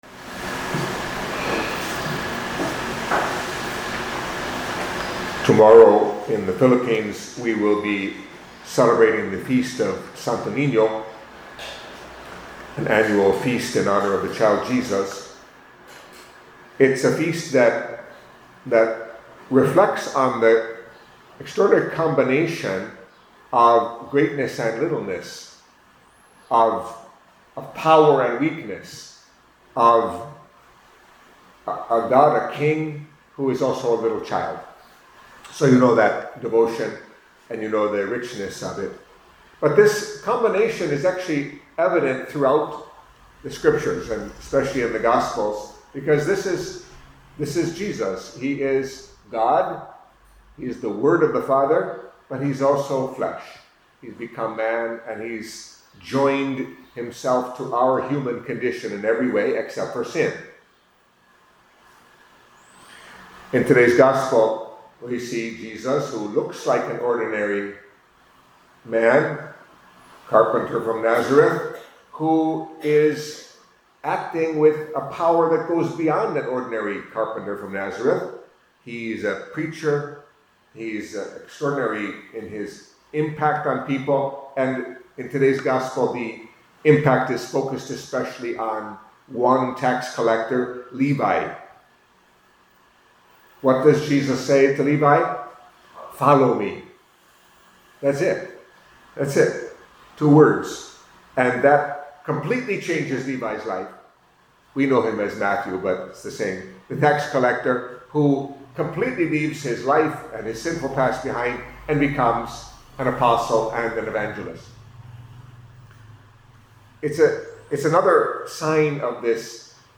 Catholic Mass homily for Saturday of the First Week in Ordinary Time